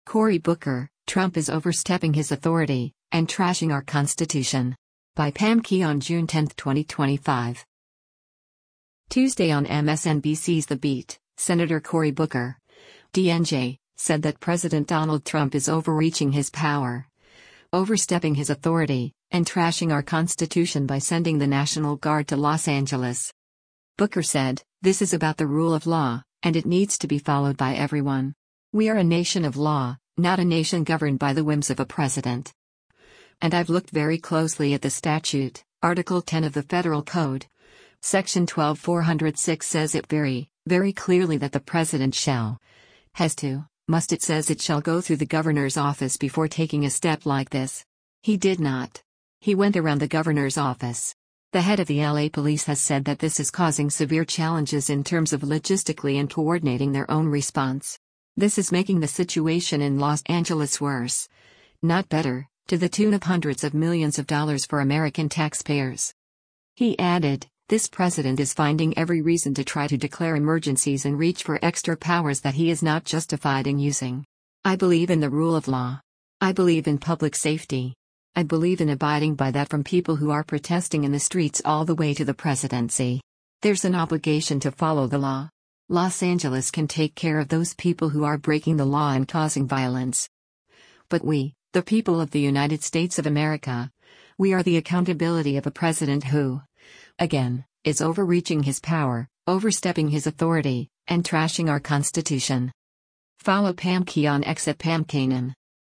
Tuesday on MSNBC’s “The Beat,” Sen. Cory Booker (D-NJ) said that President Donald Trump “is overreaching his power, overstepping his authority, and trashing our constitution” by sending the National Guard to Los Angeles.